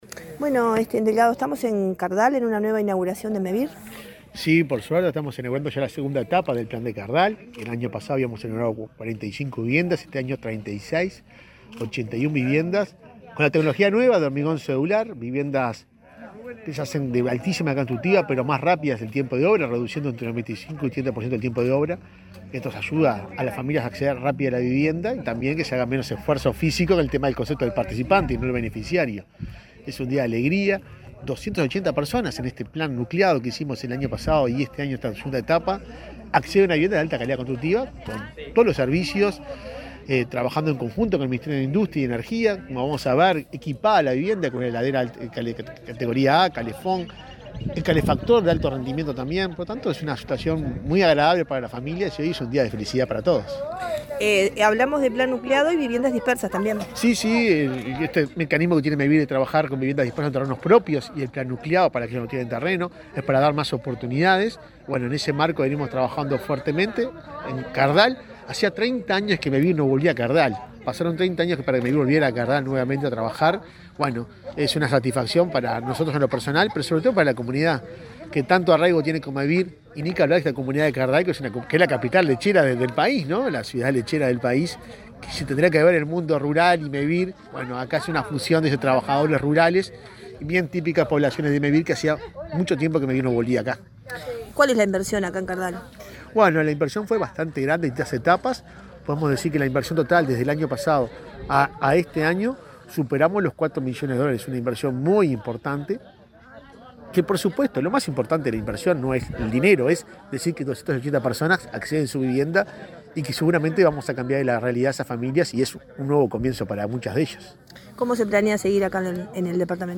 El presidente de Mevir, Juan Pablo Delgado, dialogó con la prensa en Florida, antes de inaugurar 36 viviendas en la localidad de Cardal.